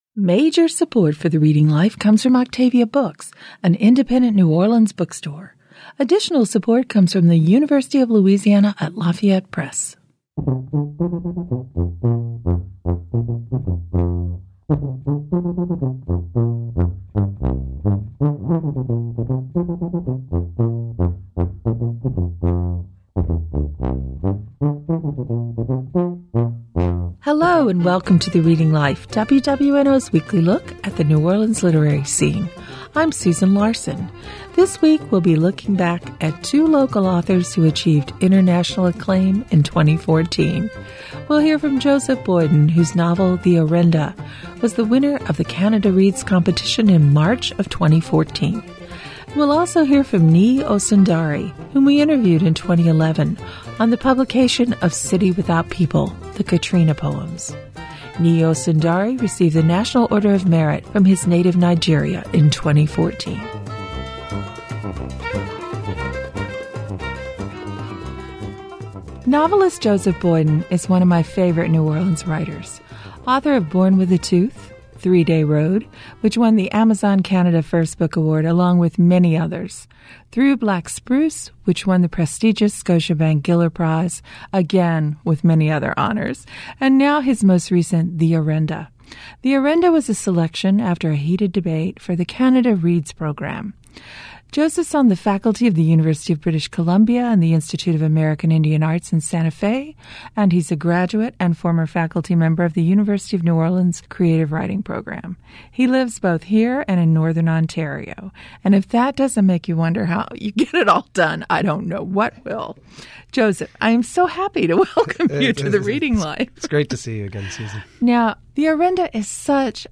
We’ll hear from Joseph Boyden, whose novel, The Orenda, was the winner of the Canada Reads competition in March of 2014. We’ll also hear from Niyi Osundare, whom we interviewed in 2011 on the publication of his poems, City Without People: The Katrina Poems .